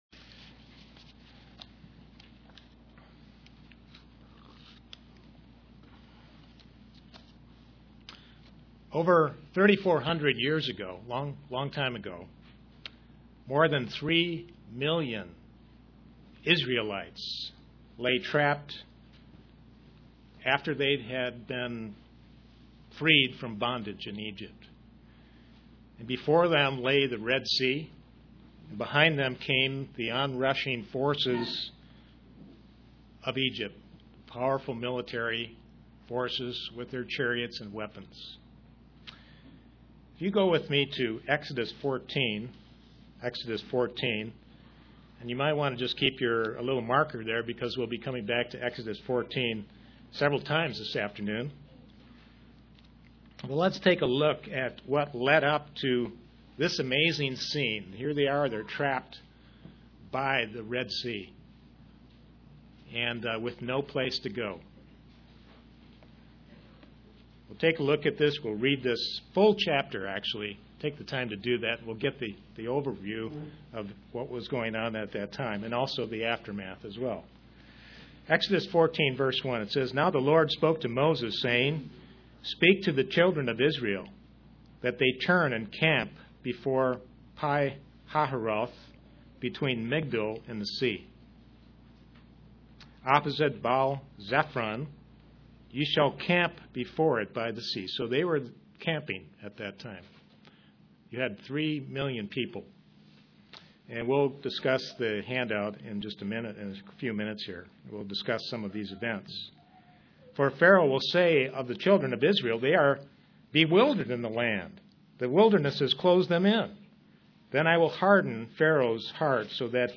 Given in Kingsport, TN
Print God promises to help us as we grow toward overcoming sin UCG Sermon Studying the bible?